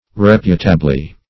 Rep"u*ta*bly, adv.